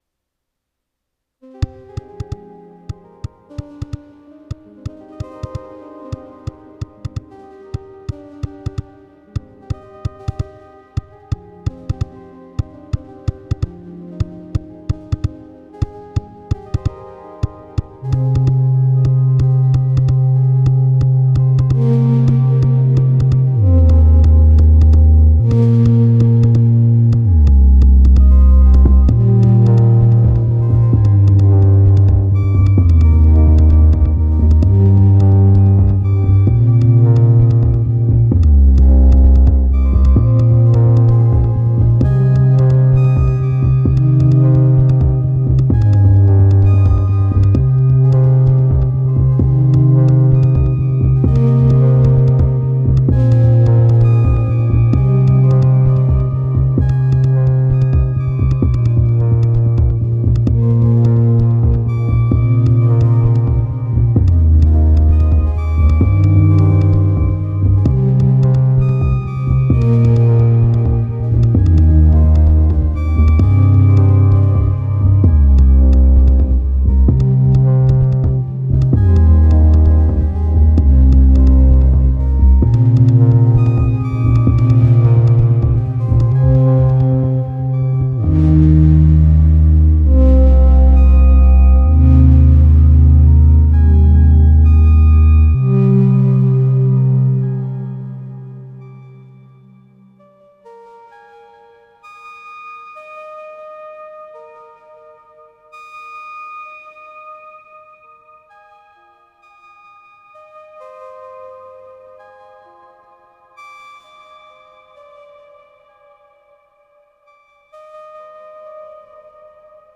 I got a Minilogue, sounds so good through CXM1978!!
Intentions: simple synth fun…